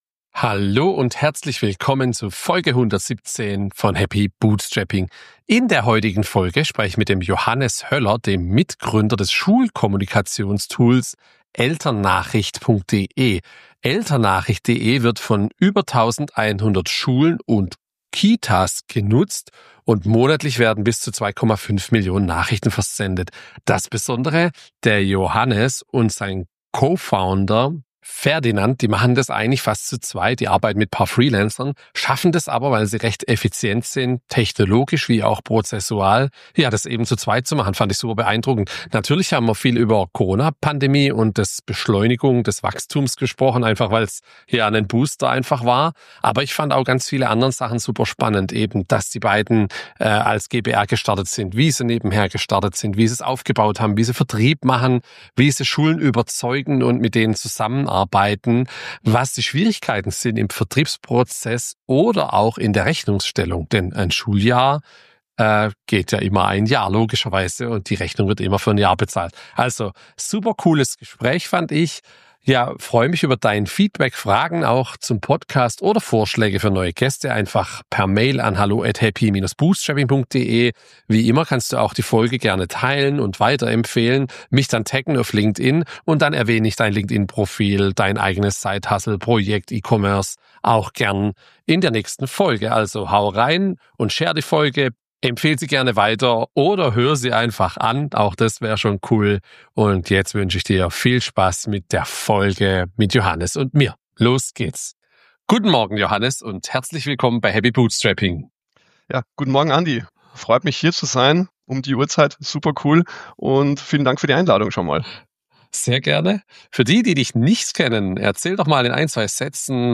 Bei "bootstrapped" Startups spricht man in der Regel von selbst finanzierten und aus den Umsätzen wachsenden Unternehmen ganz ohne Venture-Capital. Bei "Happy Bootstrapping" spreche ich mit Gründer:innen über Höhen und Tiefen des Bootstrapping und die Besonderheiten dieser Form der Unternehmensgründung.